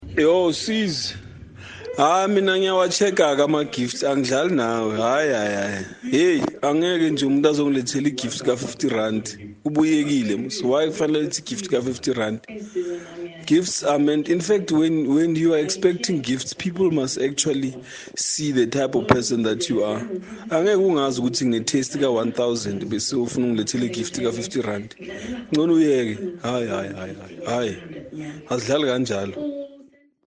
Listen to the Kaya Drive listeners responses here: